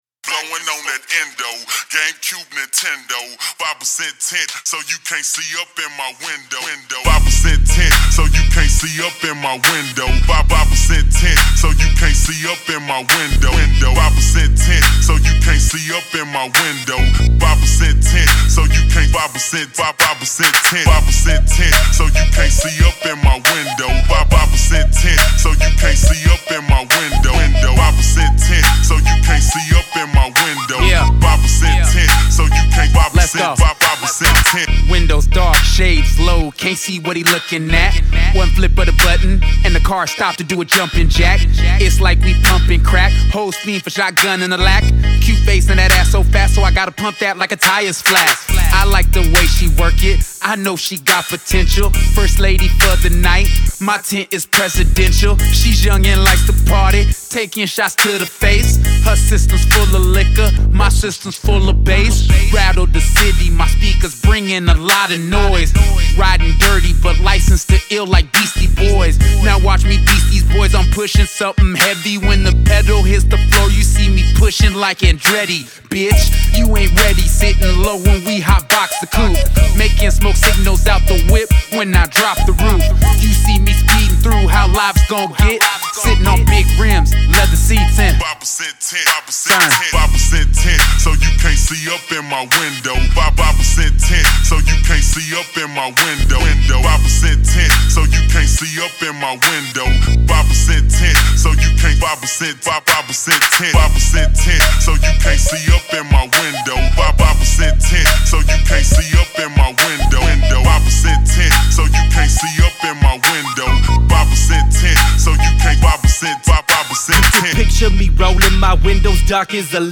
local hip-hop artists